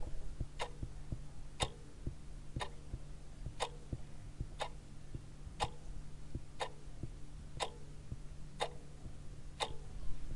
OWI sfx " Ticking clock
描述：滴答作响的时钟。使用Tascam DR40。
Tag: 计数下降 滴答滴答 滴答 OWI 心跳 滴答 二秒 时间 节拍 时钟 节拍器 炸弹 计数 滴答作响的时钟 计数